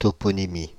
Ääntäminen
Ääntäminen Paris: IPA: [tɔ.pɔ.ni.mi] France (Île-de-France): IPA: /tɔ.pɔ.ni.mi/ Haettu sana löytyi näillä lähdekielillä: ranska Käännös 1. paikannimitutkimus 2. toponomastiikka Suku: f .